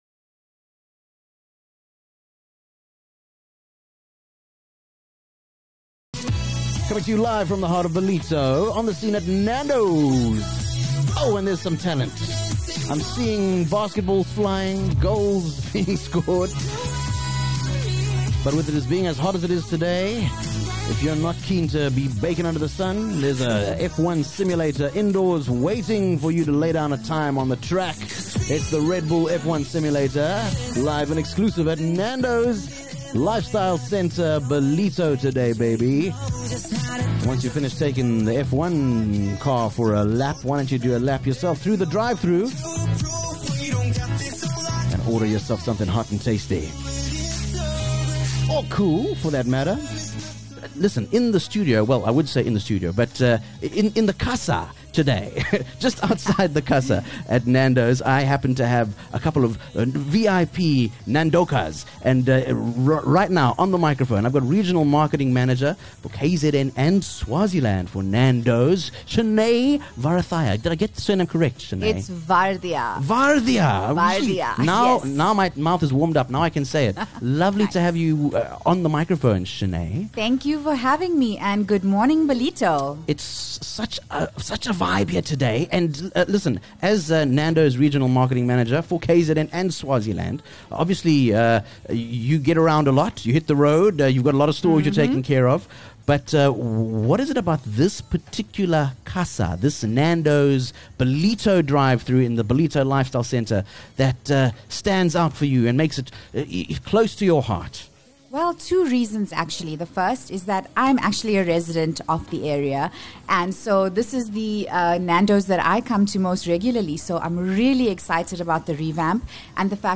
7 Oct Live from the Casa